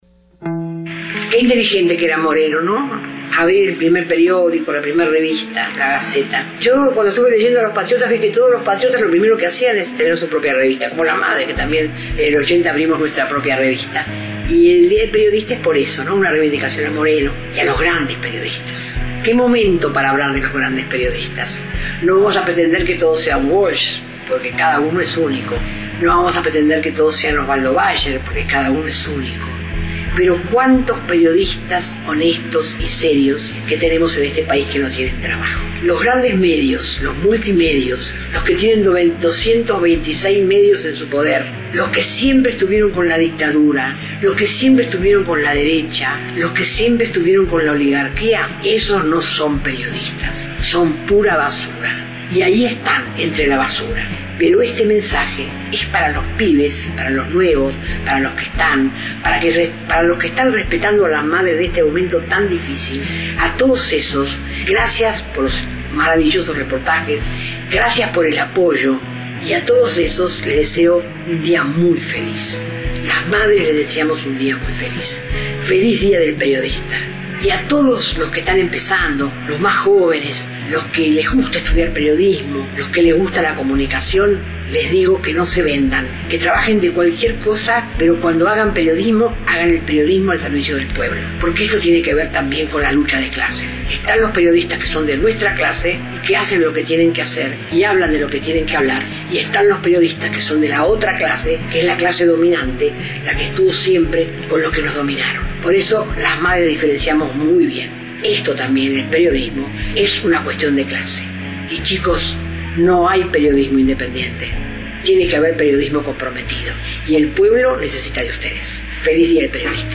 Saludos de Hebe de Bonafini en el día del periodista
SALUDO-HEBE-PERIODISTAS.mp3